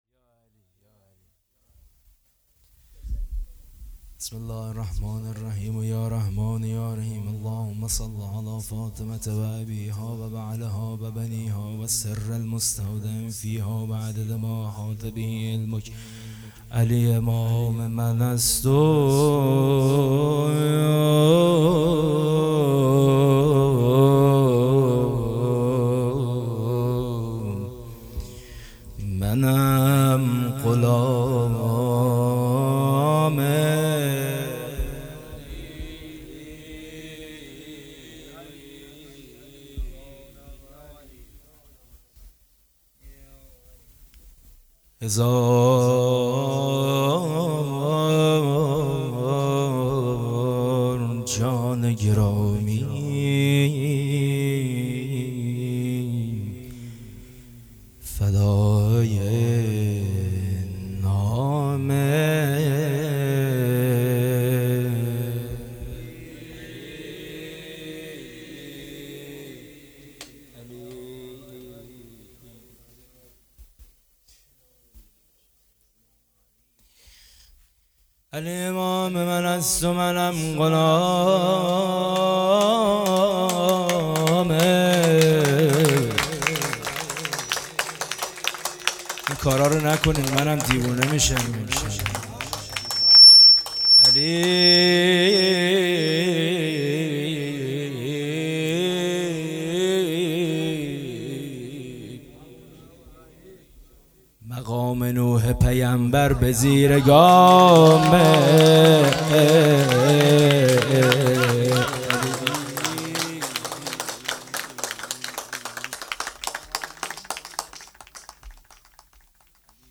جشن ولادت مولی امیرالمومنین علی علیه السلام ۷-۱۲-۹۹